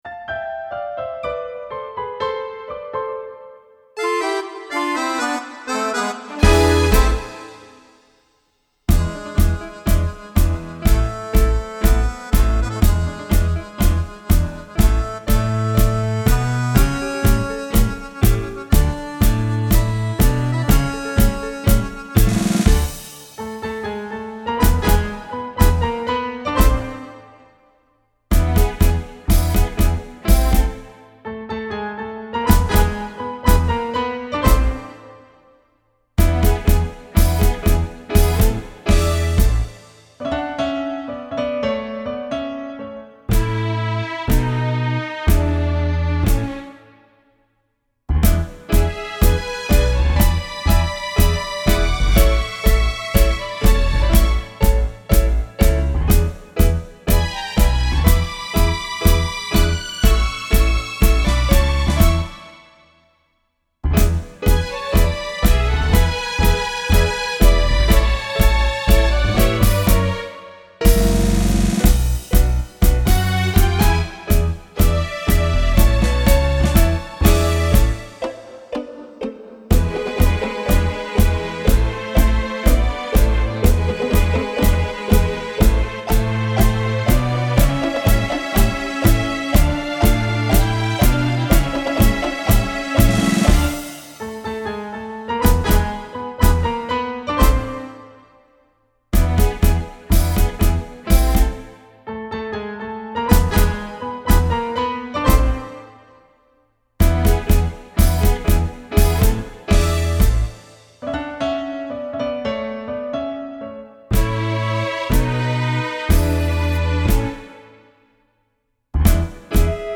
(solo base)